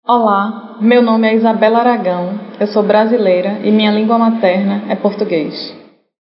For all those who do not read IPA transcriptions fluently, there are some audio recordings: Whenever you see a ? symbol next to a name, you can click on it to listen to the speaker’s own pronunciation of their name. Most speakers say something along the lines of ‘Hi, my name is […], I come from […] and my mother tongue is […]’ – all that in (one of) their native language(s).